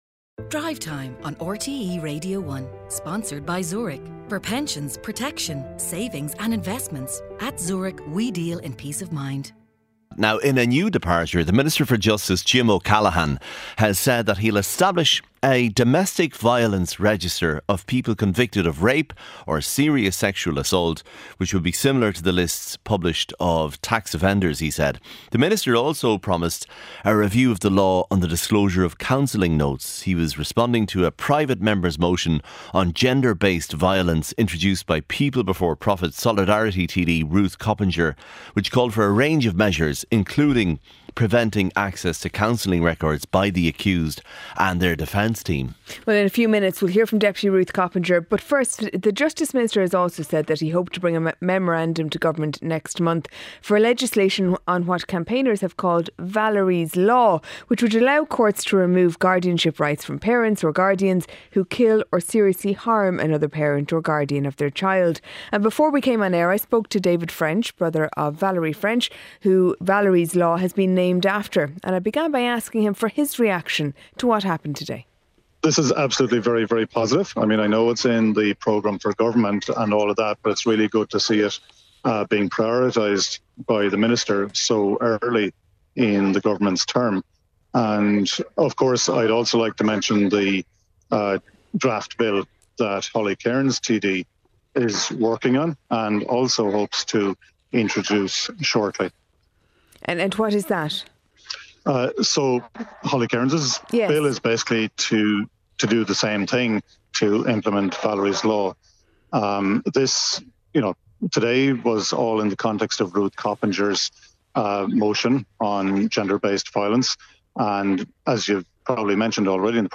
Featuring all the latest stories, interviews and special reports.